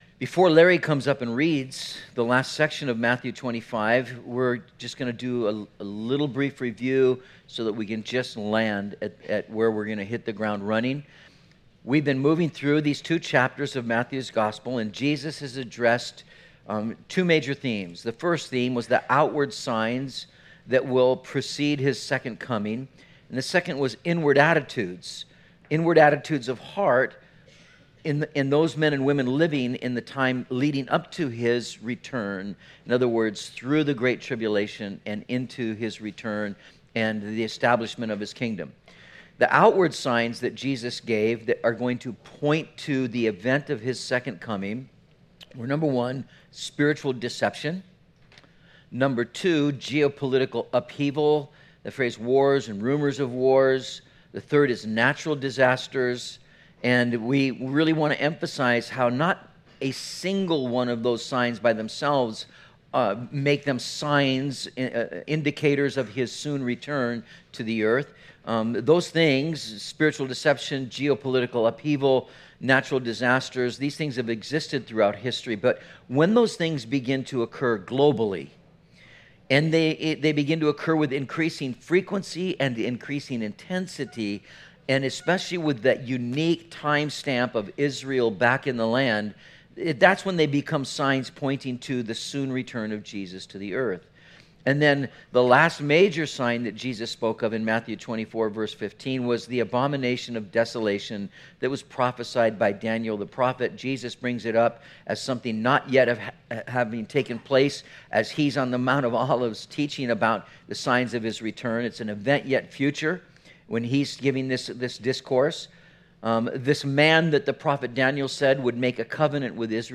04/29/19 When the King Returns - The Sheep and the Goats - Metro Calvary Sermons